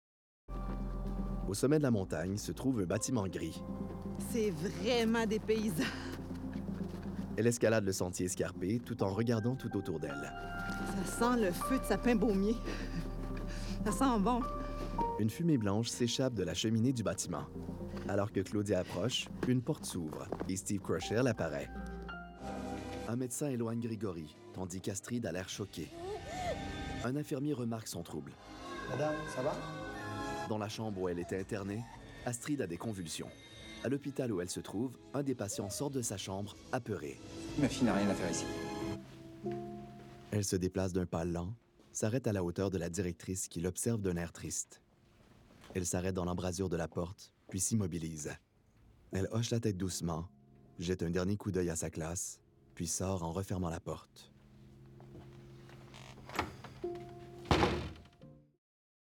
Male
Narration
Audio Description Demo Fr 2024
Words that describe my voice are radio, authentic, versatile.